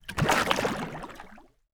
Water_28.wav